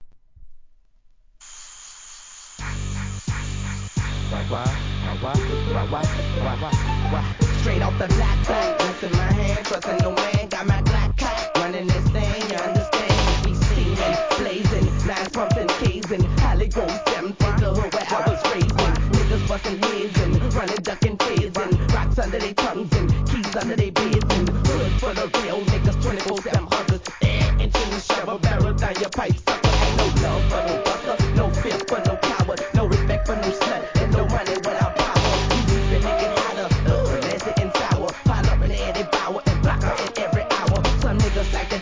HIP HOP/R&B
ハードなバウンスビート！